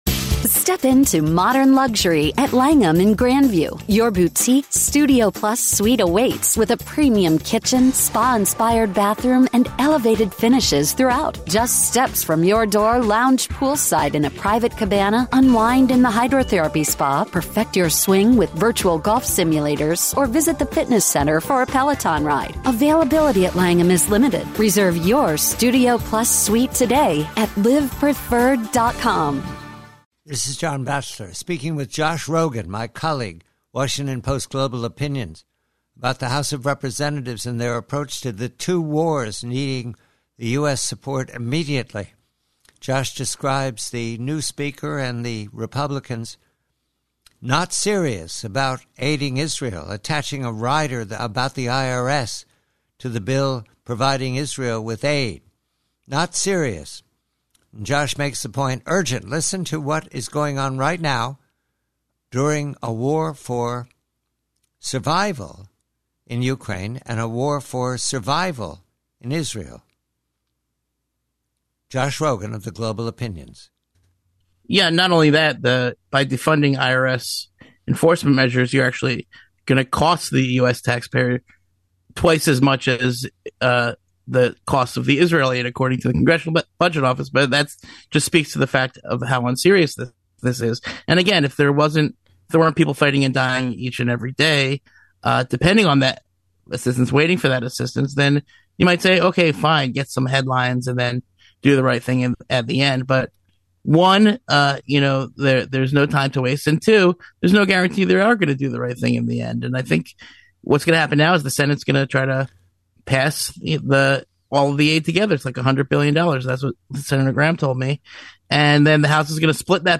PREVIEW: From a longer conversation with the Washington Post's Josh Rogin of the House game-playing with "urgent" national security support for Israel and Ukraine.